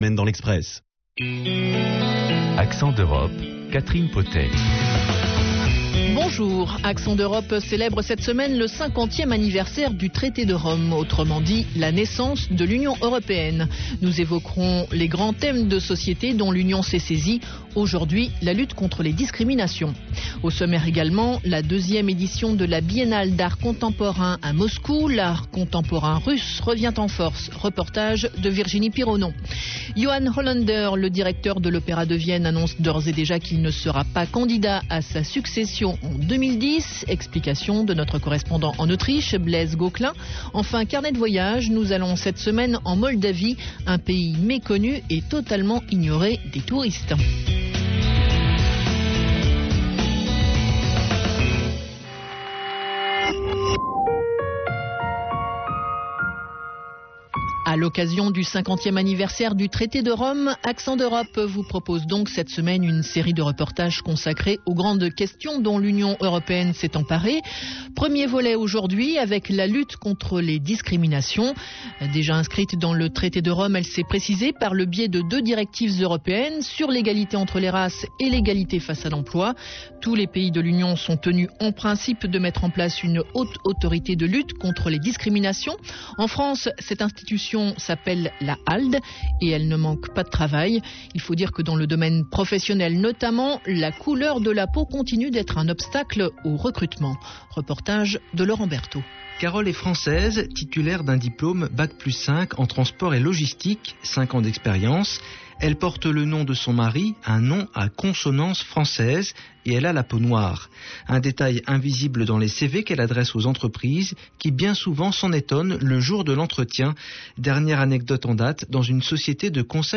Témoignages en France de salariés noirs victimes de discriminations à l'embauche et dans leur milieu professionnel.